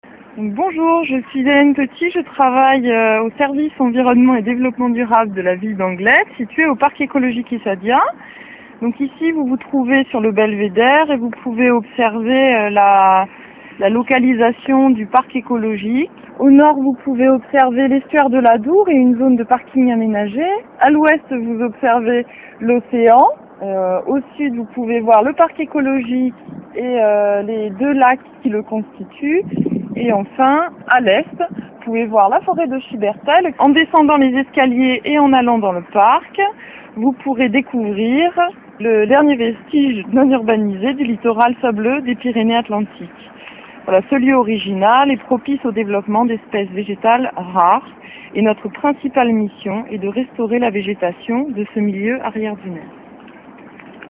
Balade audio